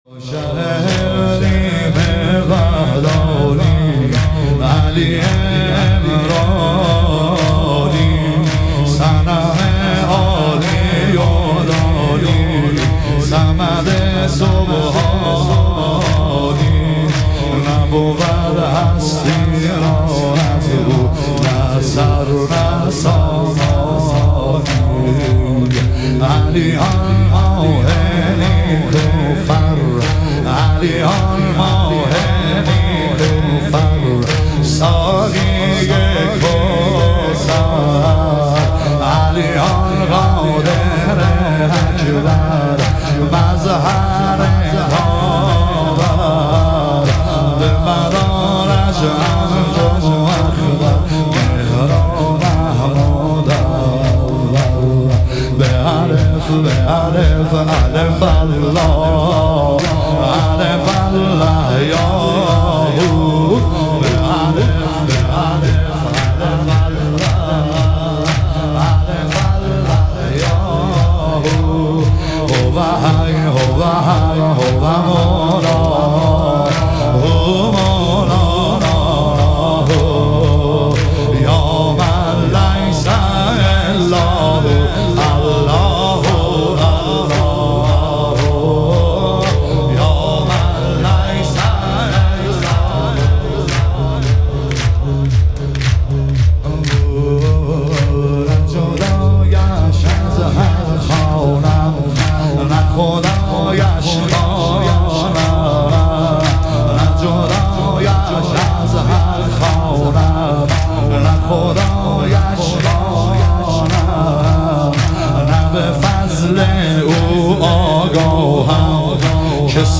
شهادت حضرت مسلم علیه السلام - تک